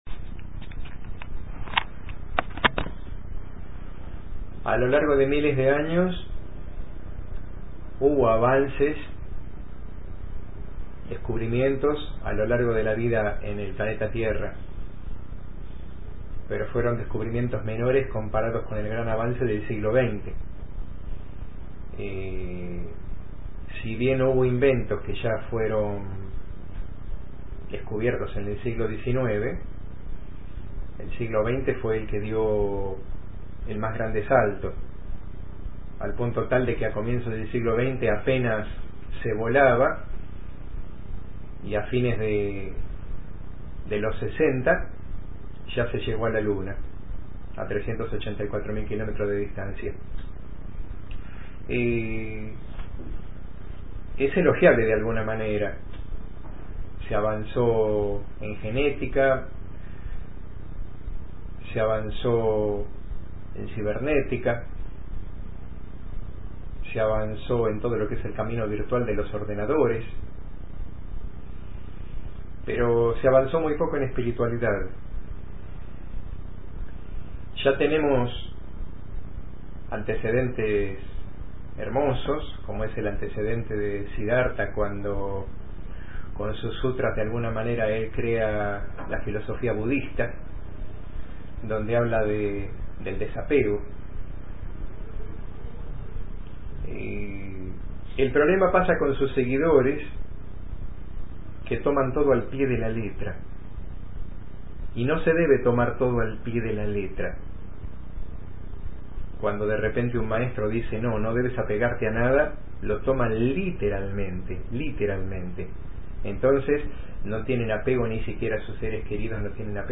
Disertación